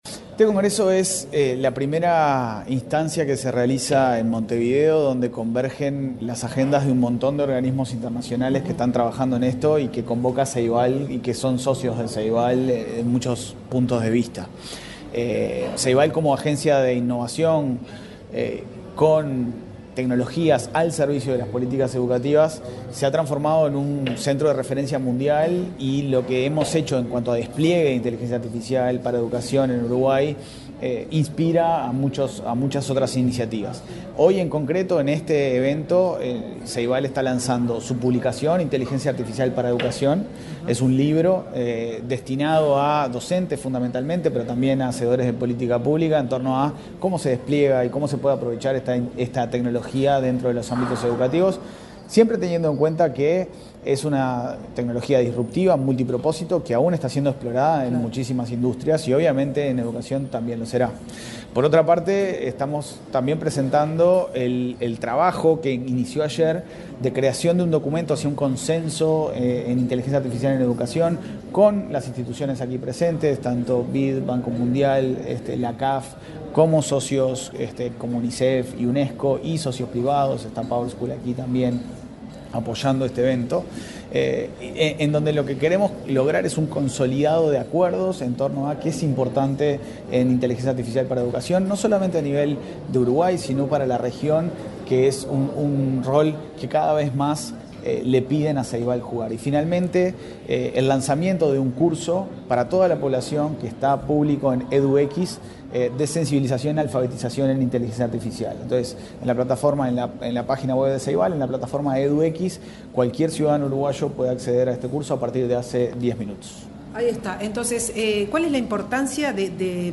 Declaraciones del presidente del Consejo de Dirección de Ceibal, Leandro Folgar
Declaraciones del presidente del Consejo de Dirección de Ceibal, Leandro Folgar 08/10/2024 Compartir Facebook X Copiar enlace WhatsApp LinkedIn El presidente del Consejo de Dirección de Ceibal, Leandro Folgar, participó, este martes 8 en Montevideo, en la apertura de un congreso internacional sobre inteligencia artificial en la educación. Luego, dialogó con la prensa.